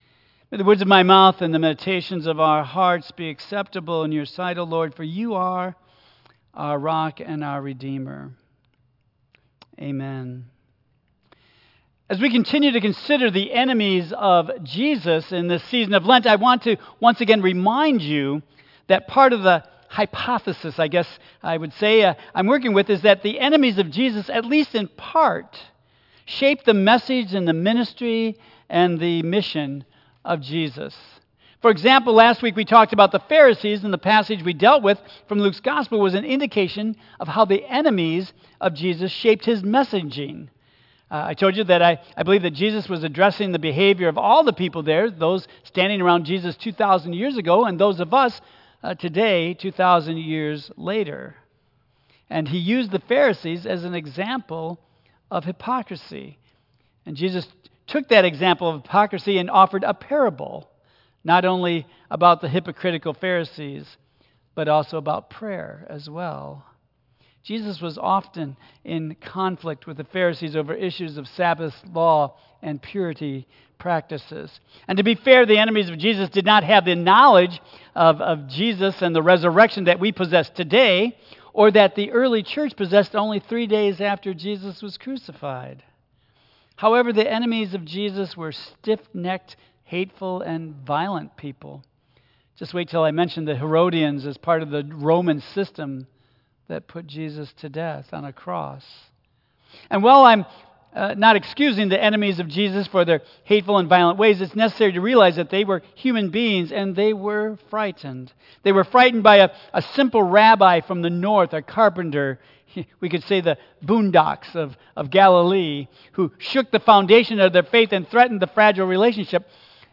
Tagged with Lent , Michigan , Sermon , Waterford Central United Methodist Church , Worship